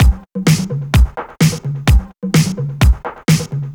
VMH1 Minimal Beats 07.wav